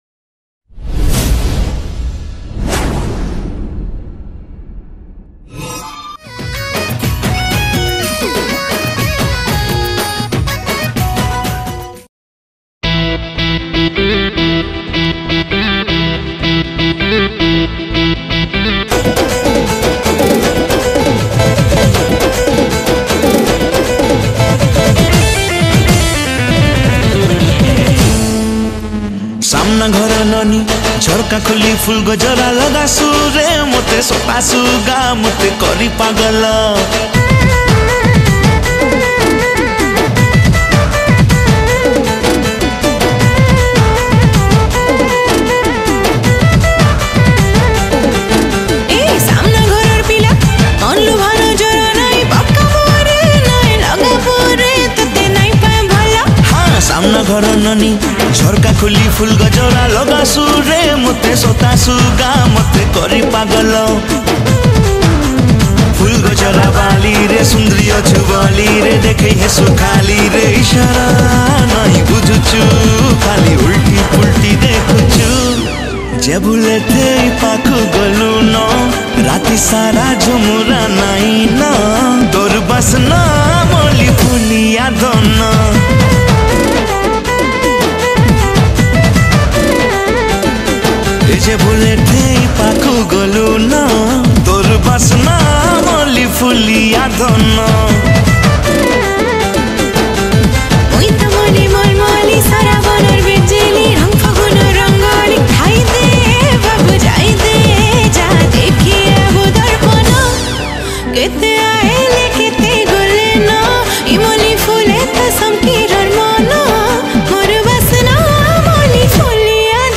***RECORDING-R.R STUDIO BARGARH
***CATEGORY--STUDIO VERSION